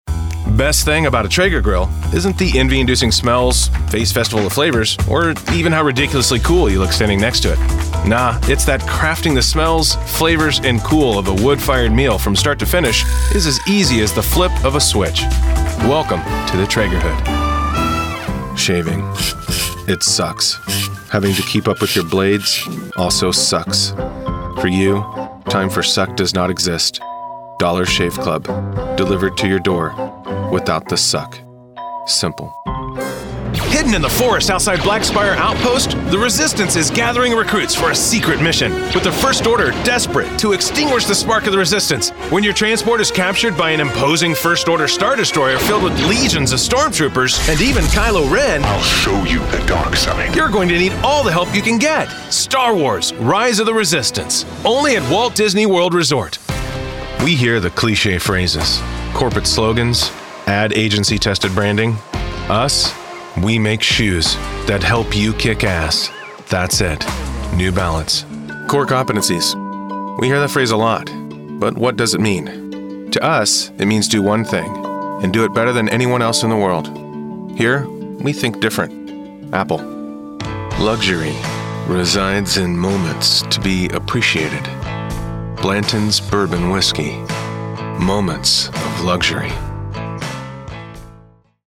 Browse professional voiceover demos.
Professionnal, soft, smile, friendly according to the project.